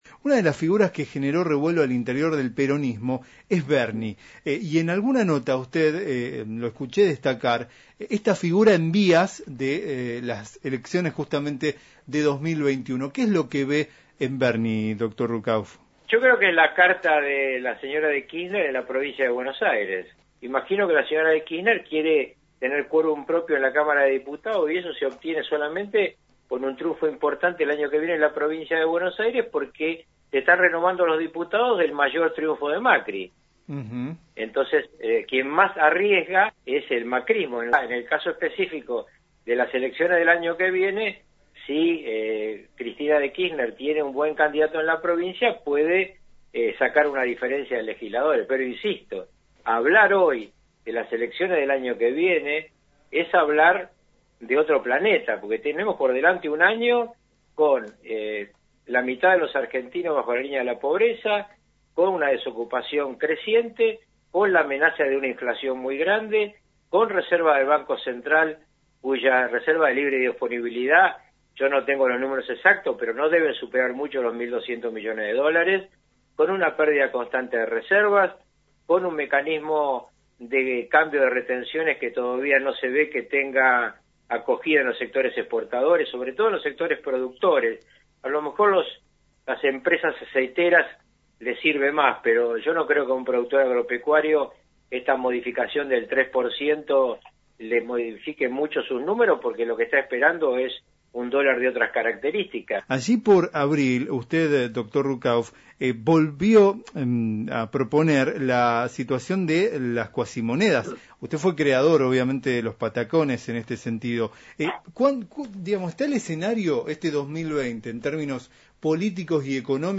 Avanzada la entrevista, Ruckauf fue implacable, al hacer una lectura de la situación actual que atraviesa el país: